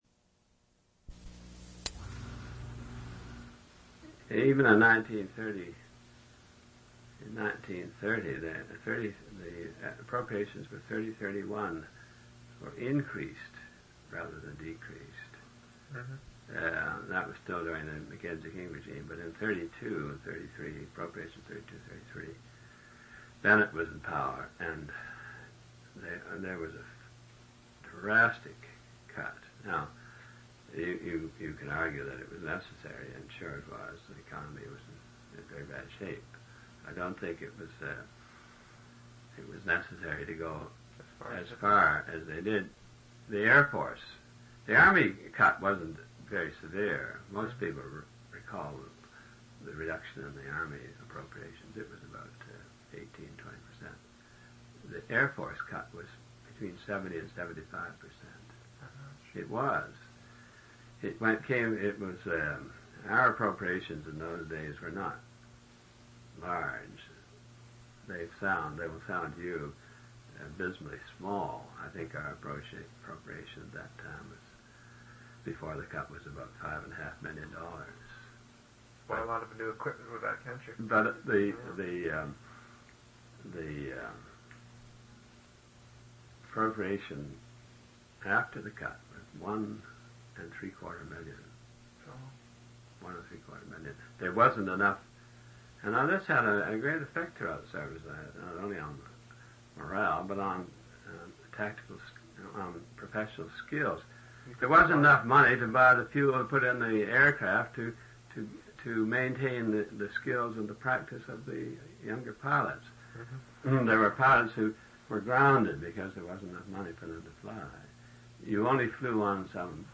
An interview/narrative of Clarence R. Dunlap's experiences during World War II. Air Marshal Dunlap, C.B.E., served with the Royal Canadian Air Force. Interview took place on March 1, 10, 17 and April 21, 1983.